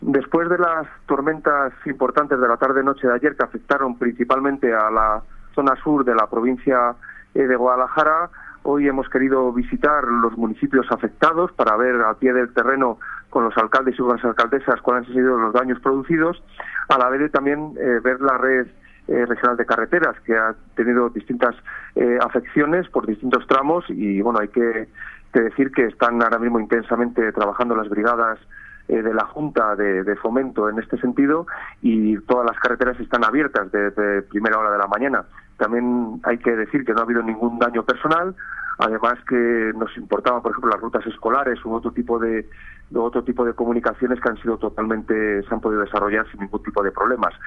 El delegado de la Junta en Guadalajara, Alberto Rojo, habla de las zonas afectadas por las lluvias en la provincia.